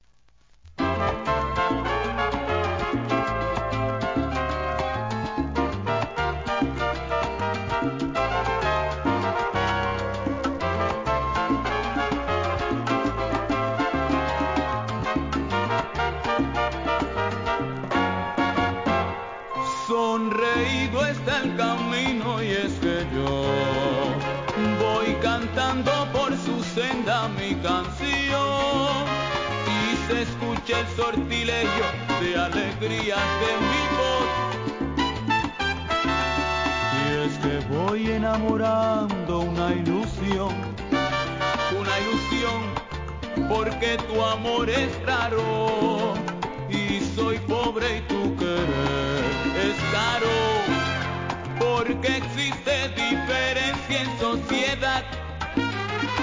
店舗 ただいま品切れ中です お気に入りに追加 1982年、サルサ・ラテンALBUM!!